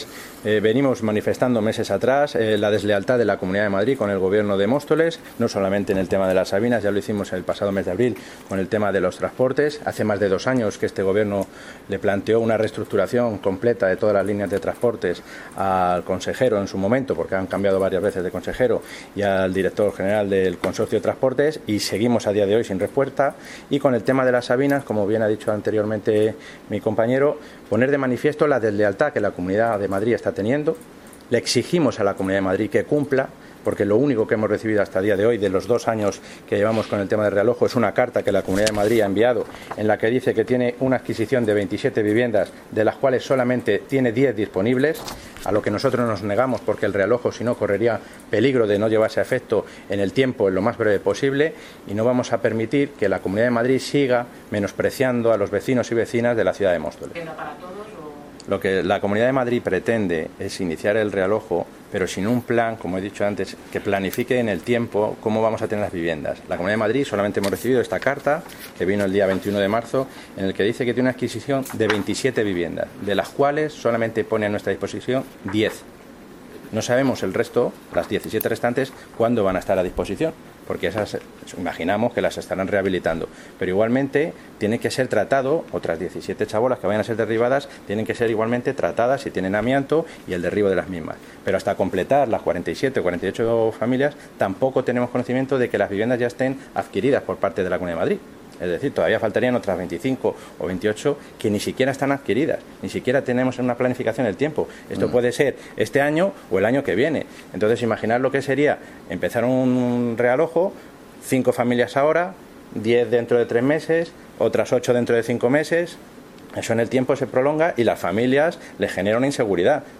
Audio - Roberto Sánchez (Concejal de Presidencia, Urbanismo, Seguridad y comunicación) Sobre poblado Las Sabinas